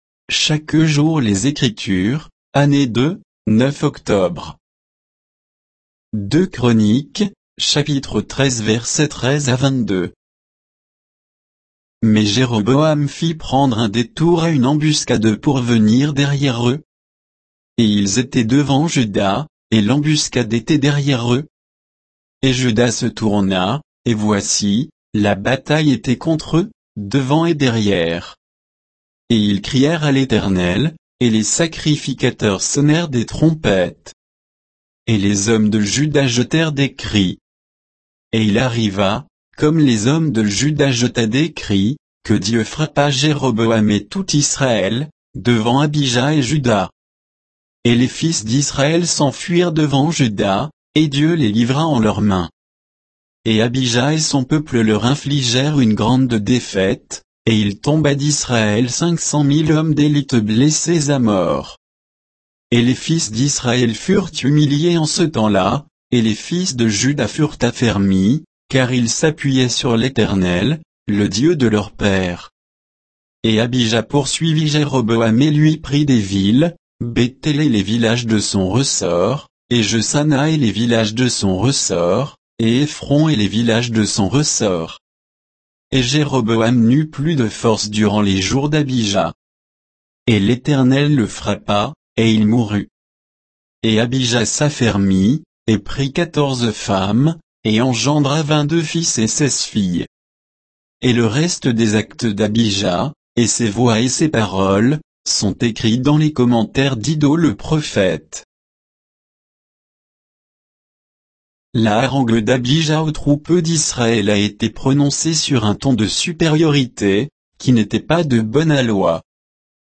Méditation quoditienne de Chaque jour les Écritures sur 2 Chroniques 13, 13 à 22